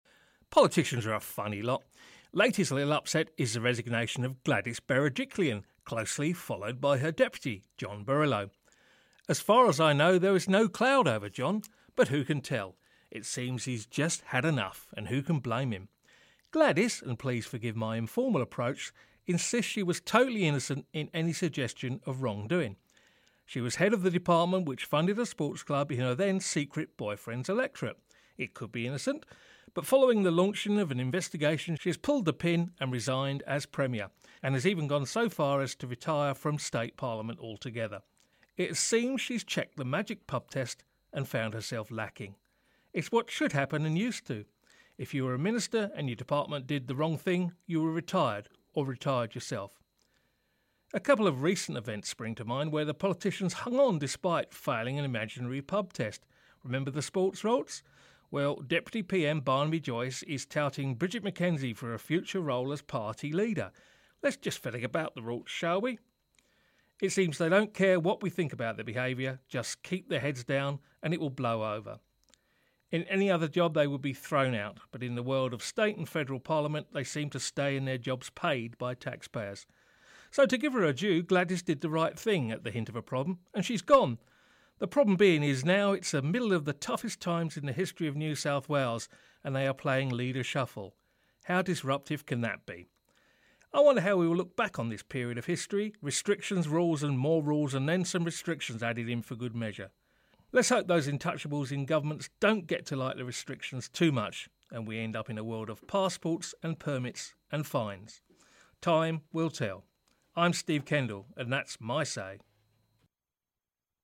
Editorial